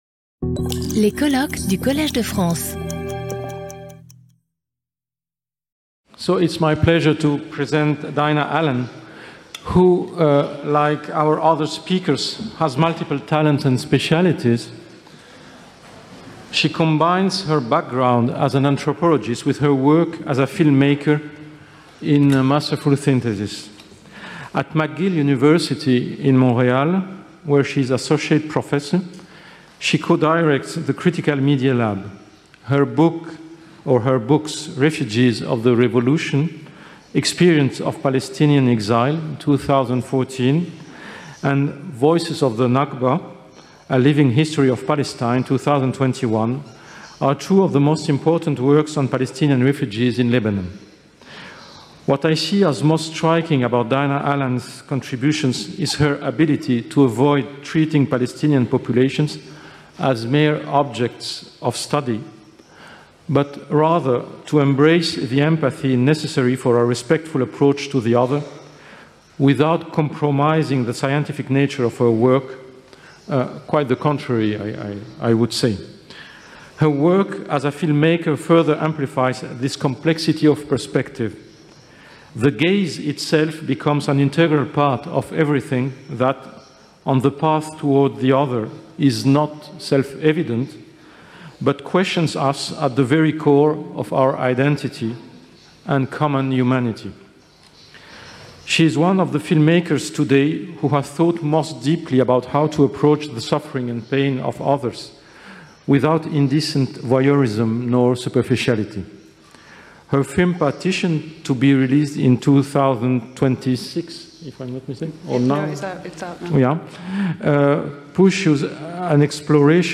Skip youtube video player Listen to audio Download audio Audio recording Cette vidéo est proposée dans une version doublée en français.